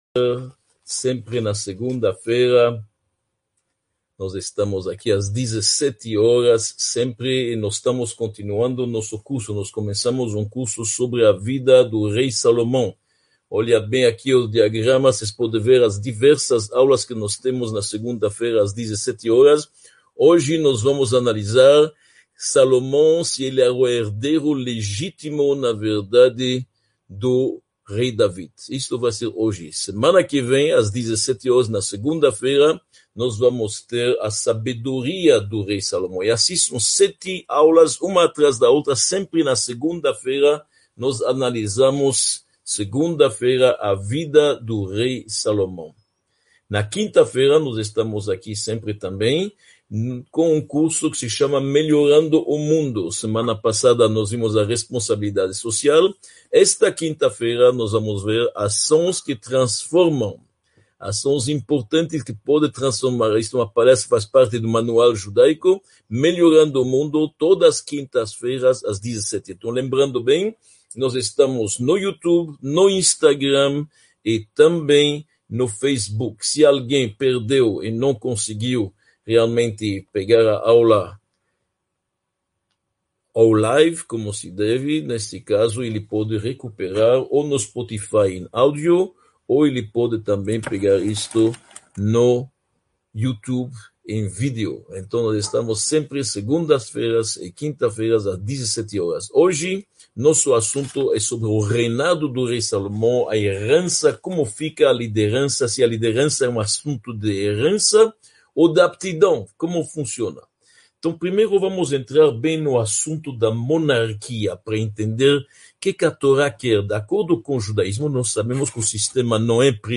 Aula 1